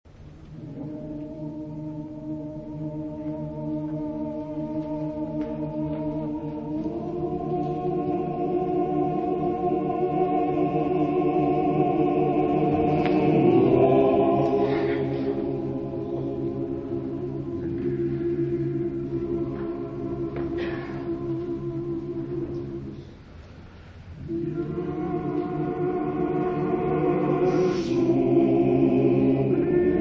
• Coro Easo [interprete]
• mottetti
• Motet